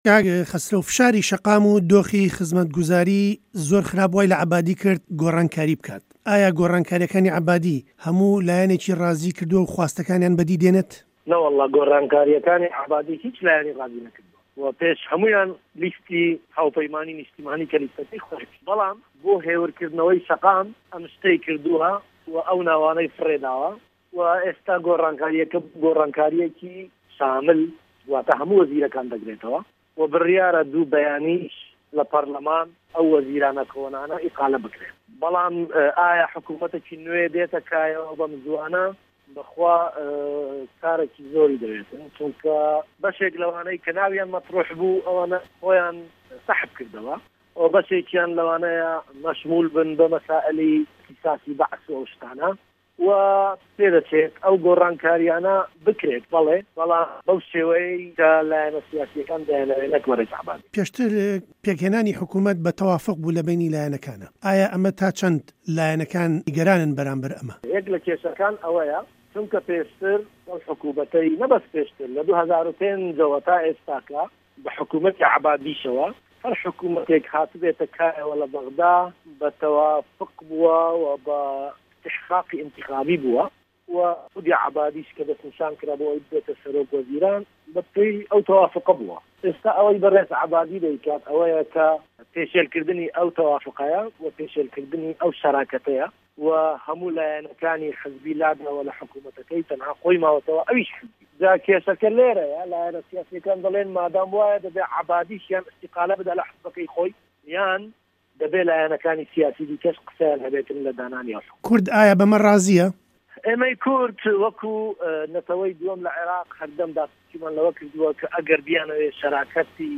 گفتووگۆ له‌گه‌ڵ خه‌سره‌و گۆران